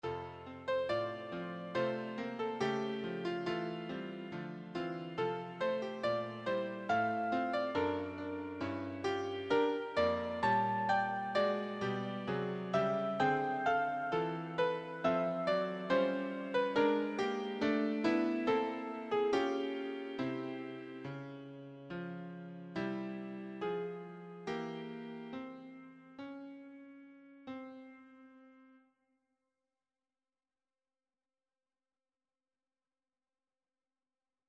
choir SATB
Love songs